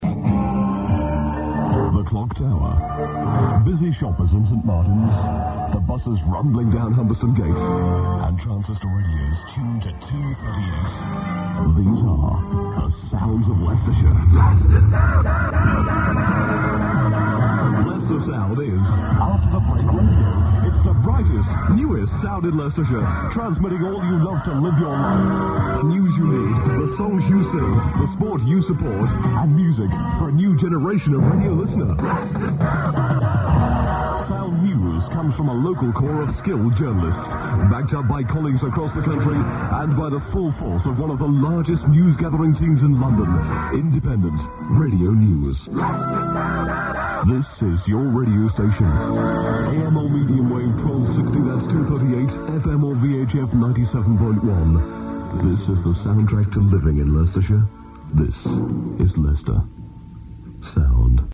I've had this on before but not in stereo!